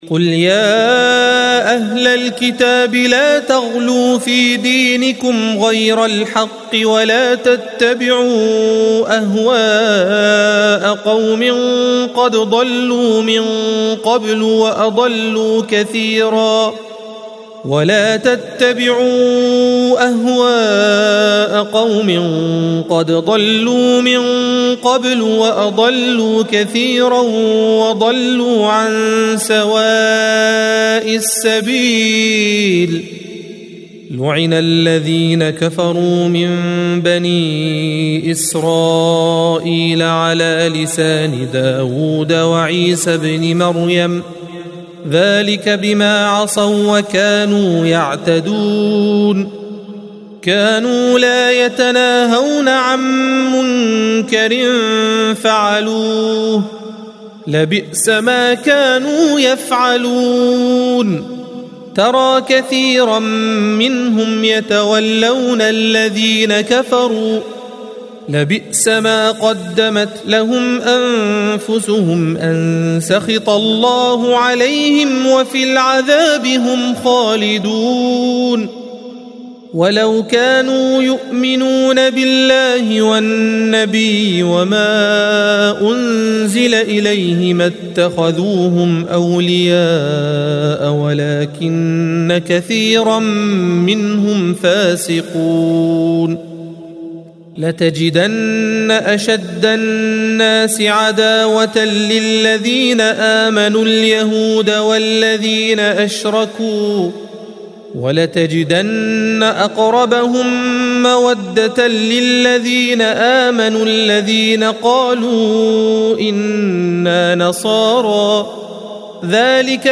الصفحة 121 - القارئ